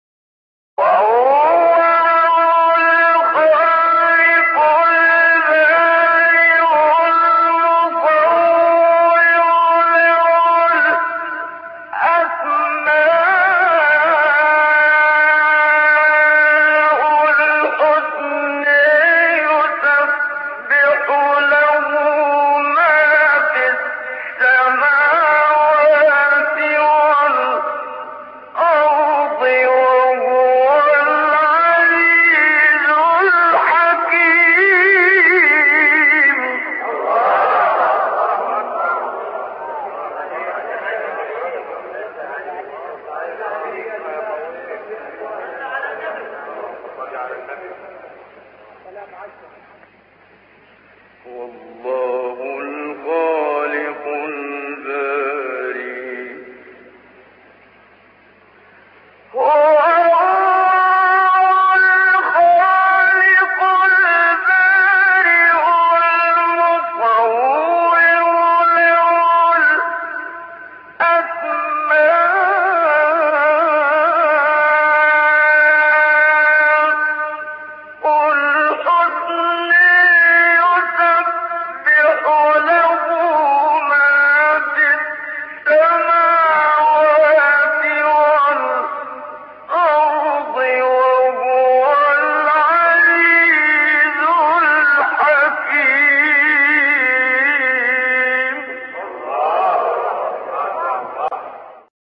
فرازی زیبا از تلاوت قرآن